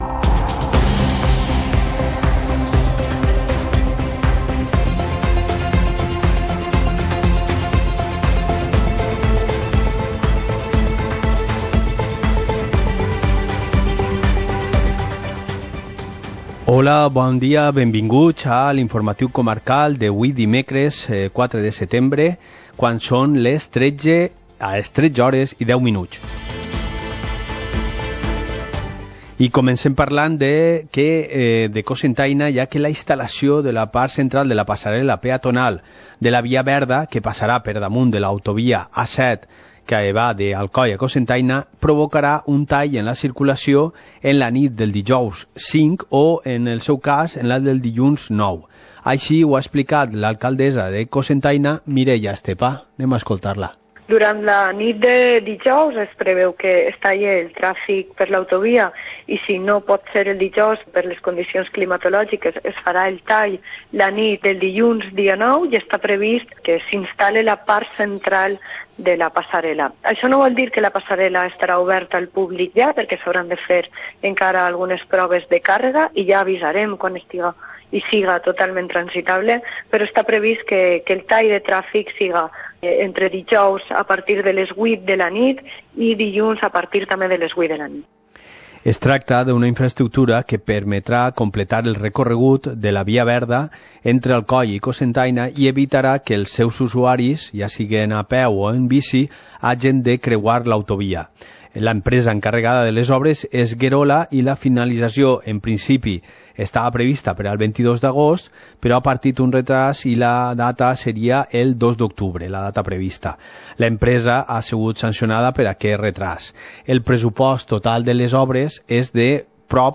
Informativo comarcal - miércoles, 04 de septiembre de 2019